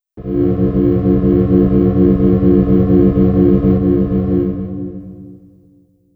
Campanas en mi cabeza